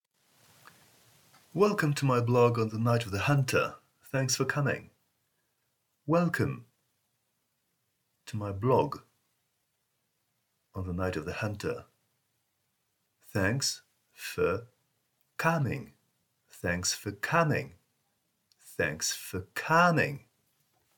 Pronunciation : the letter O :